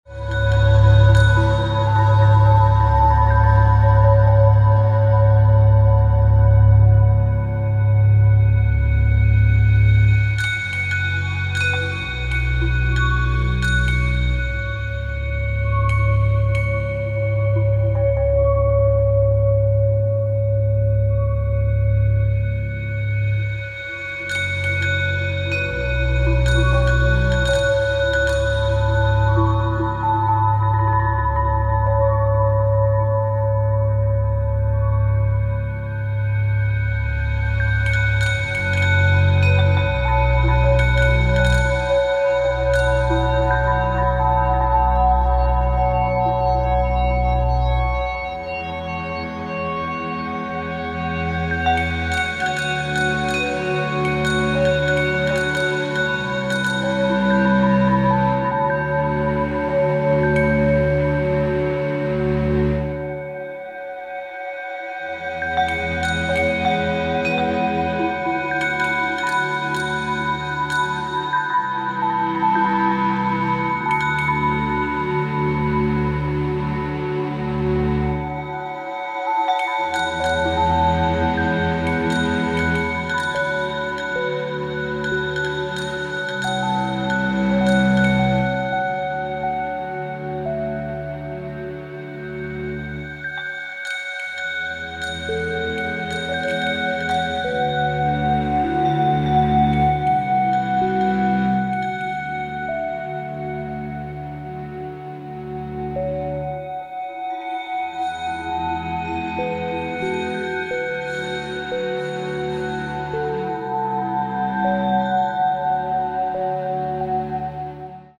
guitars and bells